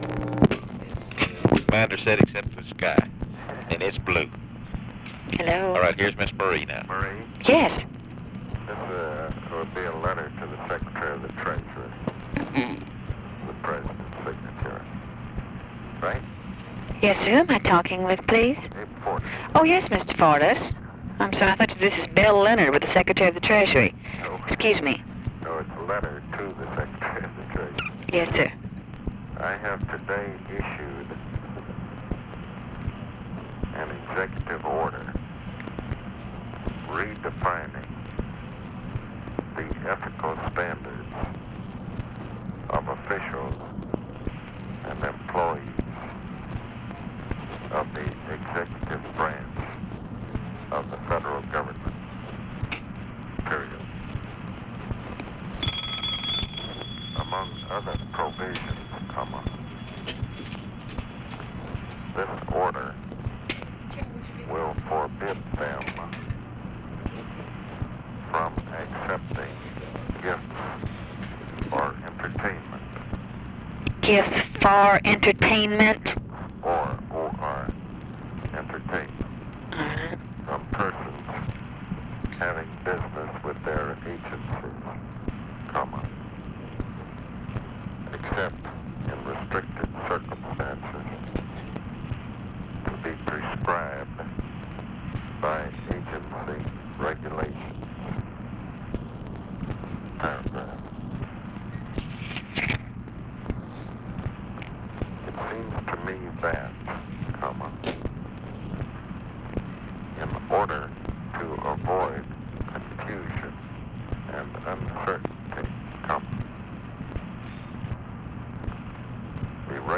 Conversation with MARVIN WATSON?
Secret White House Tapes | Lyndon B. Johnson Presidency Conversation with MARVIN WATSON?